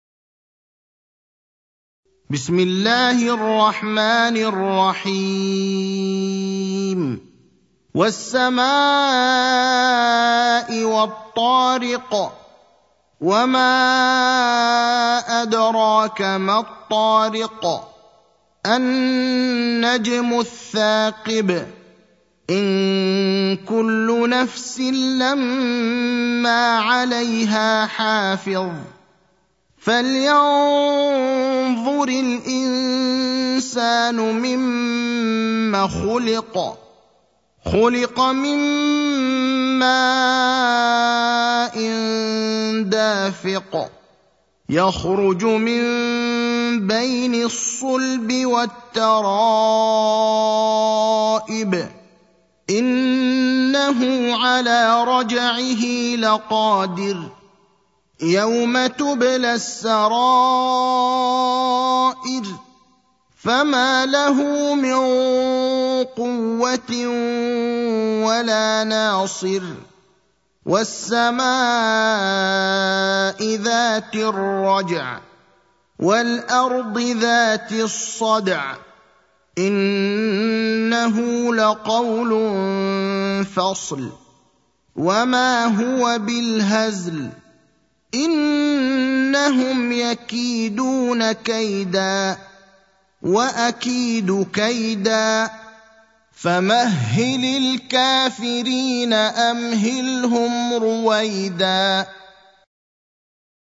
المكان: المسجد النبوي الشيخ: فضيلة الشيخ إبراهيم الأخضر فضيلة الشيخ إبراهيم الأخضر الطارق (86)